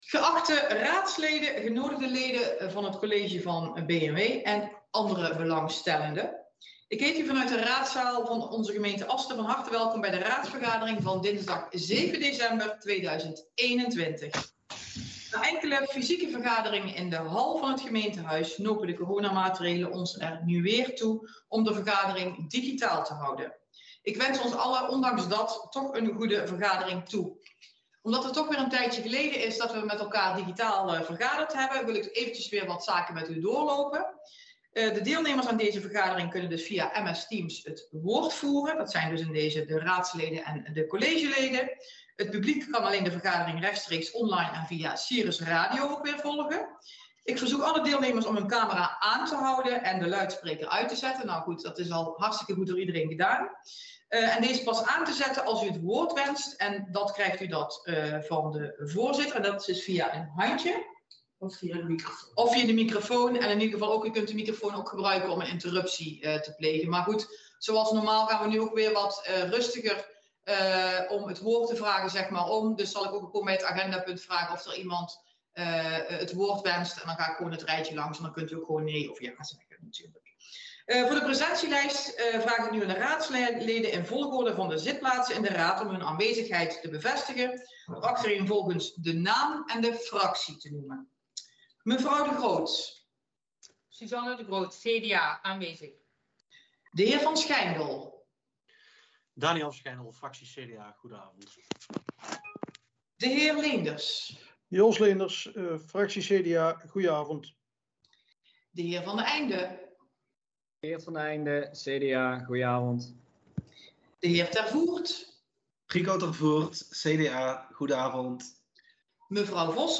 Geluidsopnamen raadsvergadering Asten 7 dec 2021.mp3